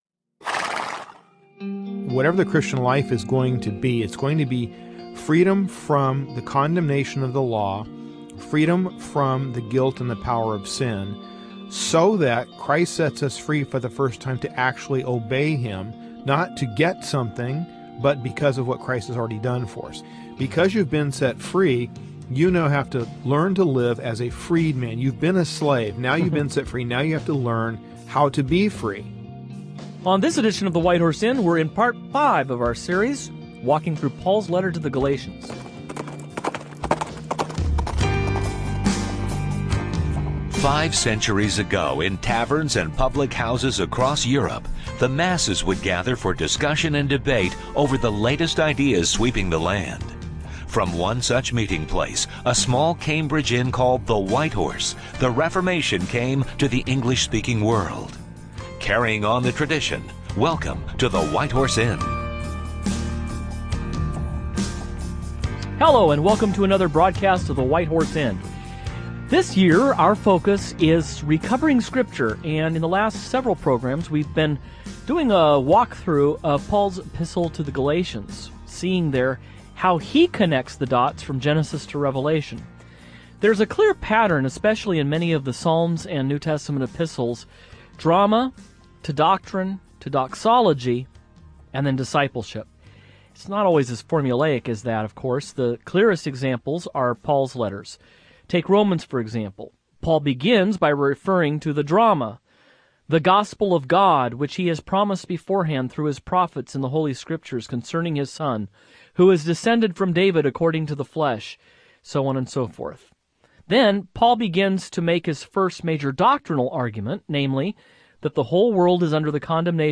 What does it mean to walk by the Spirit? How does our sanctification relate to our freedom in Christ and justification? The hosts discuss these issues and more as they conclude their five part series through Paul's letter the to the Galatians.